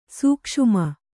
♪ sūkṣuama